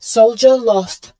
marine_soldierlost2.wav